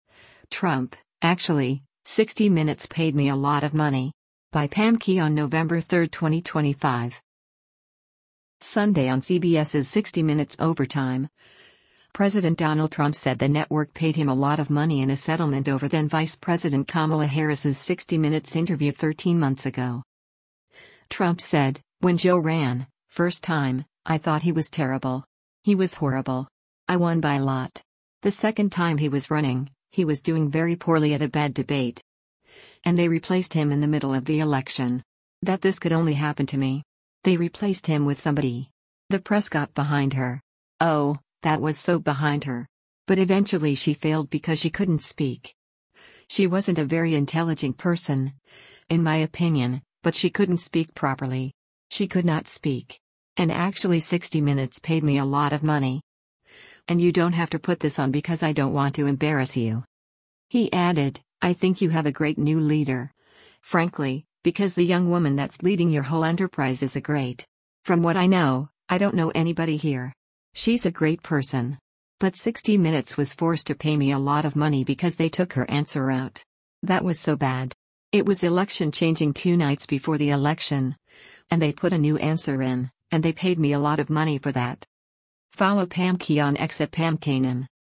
Sunday on CBS’s “60 Minutes Overtime,” President Donald Trump said the network paid him “a lot of money” in a settlement over then-Vice President Kamala Harris’s “60 Minutes” interview 13 months ago.